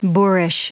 Prononciation du mot boorish en anglais (fichier audio)
Prononciation du mot : boorish